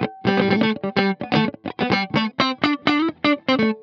22 GuitarFunky Loop A.wav